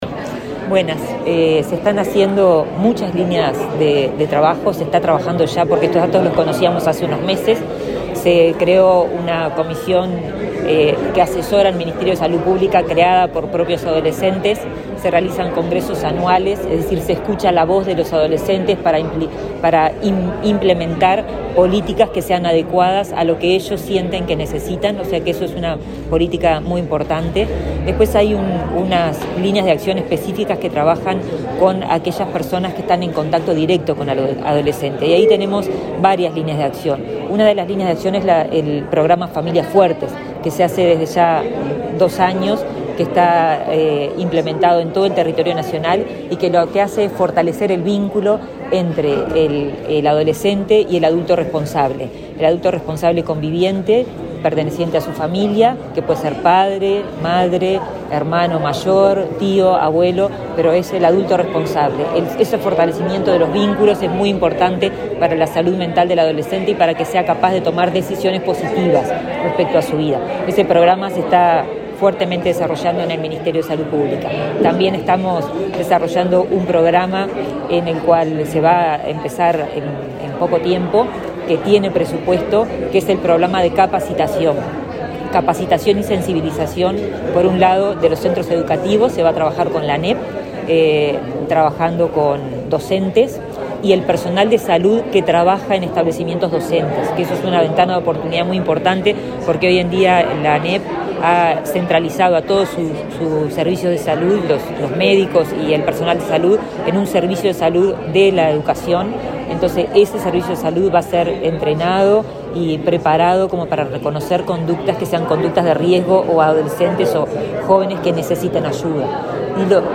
Declaraciones de la ministra de Salud Pública, Karina Rando
Declaraciones de la ministra de Salud Pública, Karina Rando 10/10/2023 Compartir Facebook X Copiar enlace WhatsApp LinkedIn Este martes 10 en Montevideo, la ministra de Salud Pública, Karina Rando, dialogó con la prensa luego de participar de la presentación de un manual de prevención del suicidio adolescente.